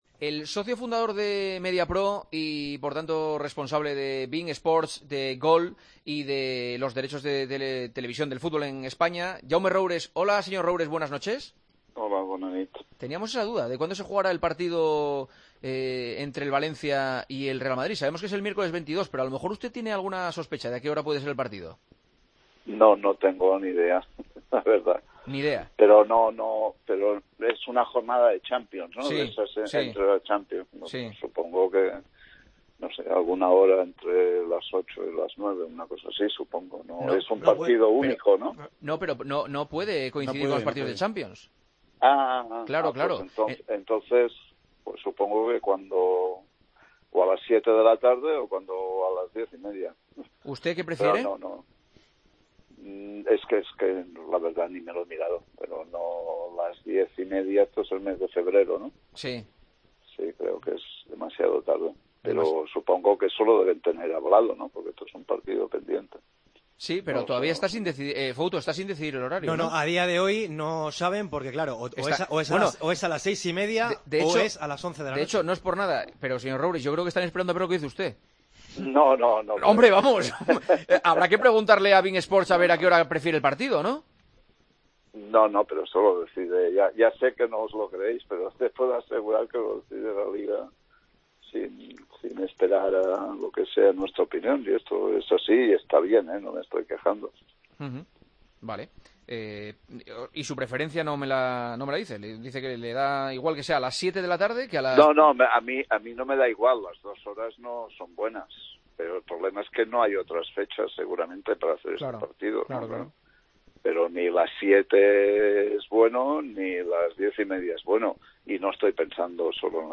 AUDIO: Juanma Castaño entrevistó al socio fundador de Mediapro, tras la confirmación de la llegada del 'VAR' a LaLiga: "Nuestras...